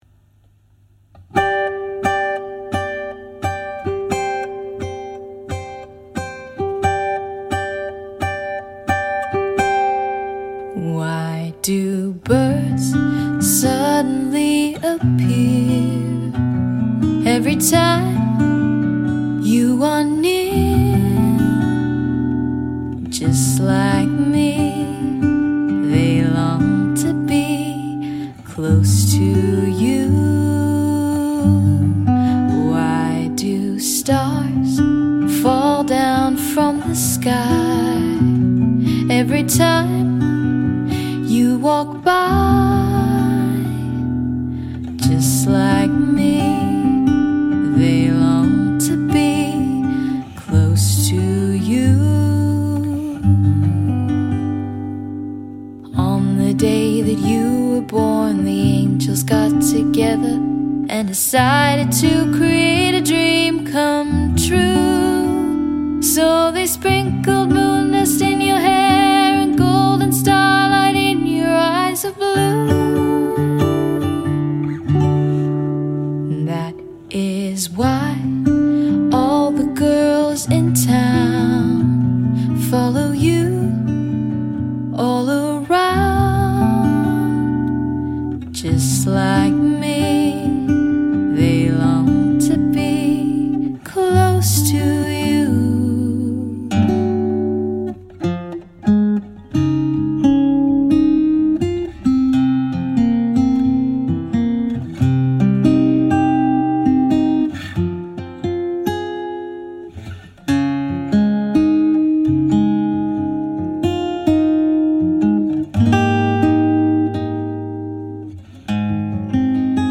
Charming acoustic duo boasting a colourful repertoire.